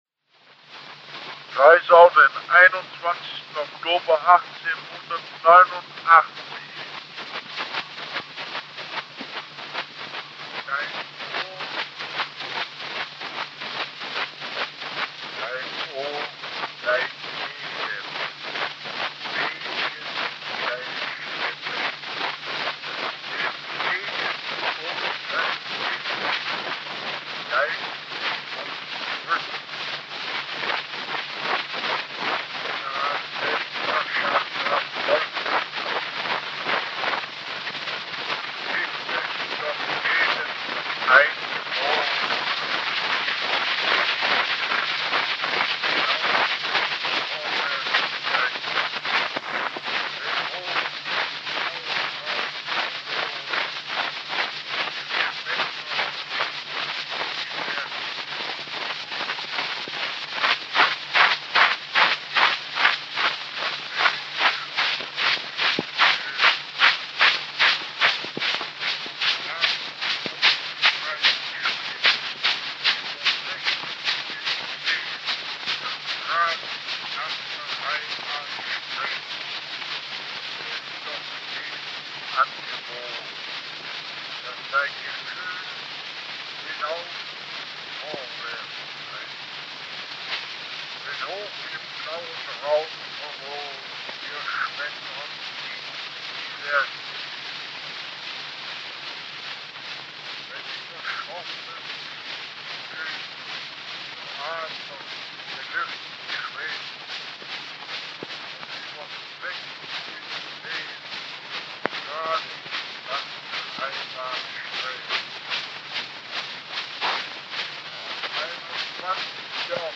2012년, 토머스 에디슨 연구소 부지에서 비스마르크와 몰트케의 육성이 녹음된 축음기의 왁스 실린더가 발견되었다. 이 녹음은 1889년에 이루어졌으며, 몰트케는 셰익스피어의 작품과 괴테『파우스트』 일부를 낭독했다. 1800년에 태어난 몰트케는 현재까지 육성이 남아있는 인물 중 세계에서 가장 나이가 많은 사람으로 여겨진다.[367]